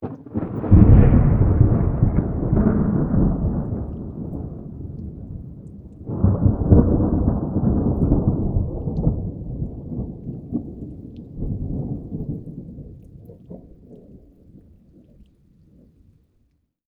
THUNDER_Clap_Rumble_04_stereo.wav